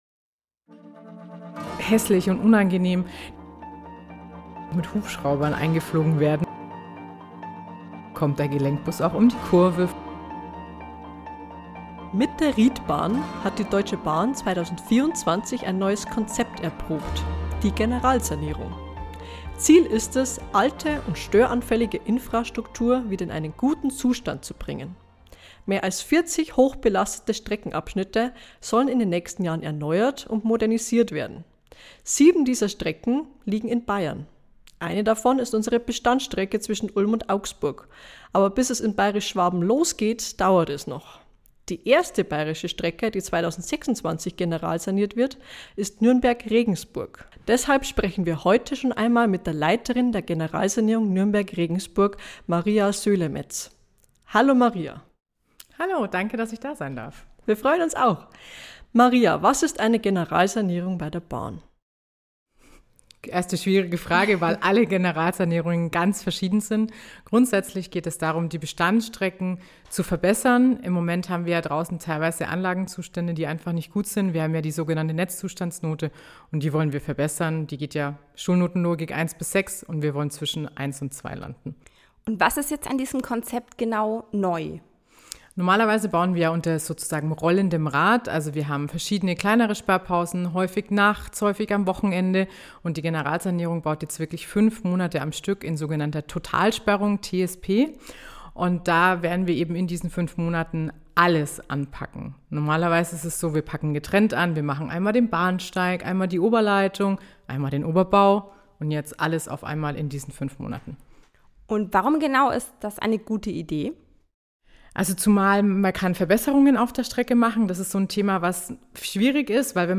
Die erste bayerische Strecke ist Nürnberg-Regensburg. Ein Gespräch